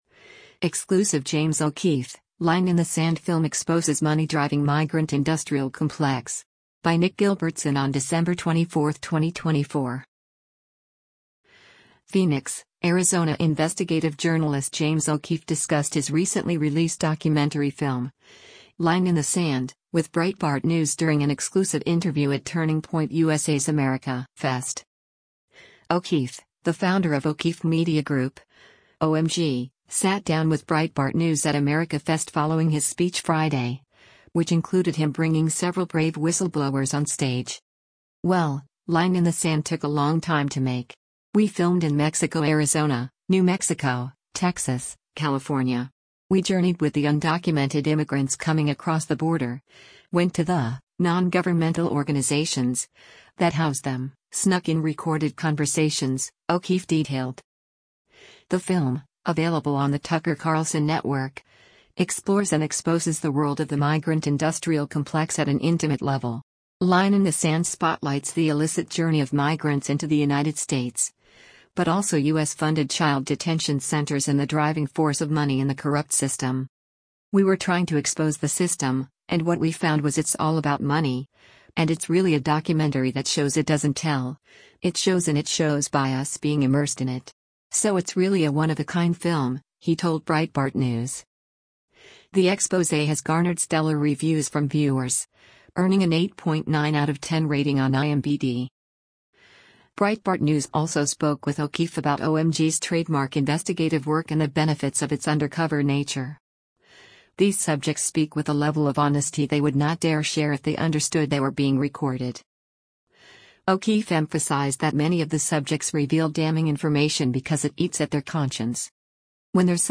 PHOENIX, ARIZONA–Investigative journalist James O’Keefe discussed his recently released documentary film, Line in the Sand, with Breitbart News during an exclusive interview at Turning Point USA’s AmericaFest.